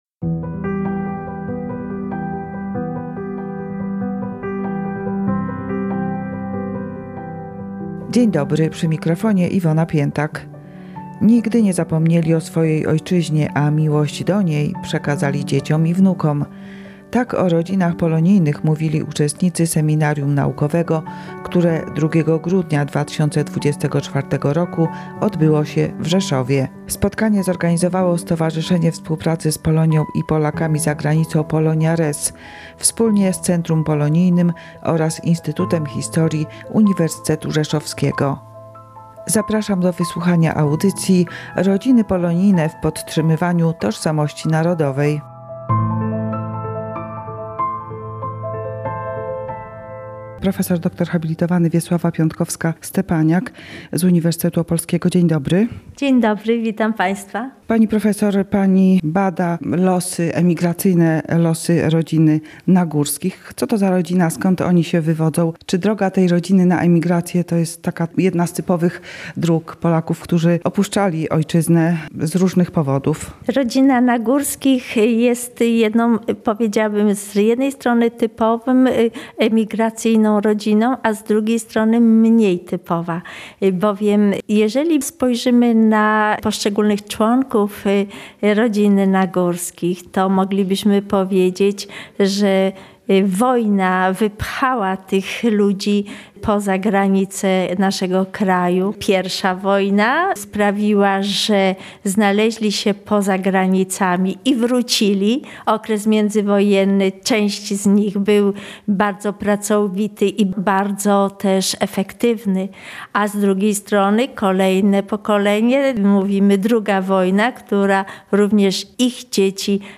O znaczeniu rodzin polonijnych w podtrzymywaniu tożsamości narodowej mówiono w czasie seminarium naukowego 2 grudnia 2024 w Domu Polonii w Rzeszowie.
Naukowcy z kilku polskich uczelni dyskutowali o emigracji Polaków w różnych okresach historycznych – począwszy od powstania listopadowego aż po emigrację po II wojnie światowej.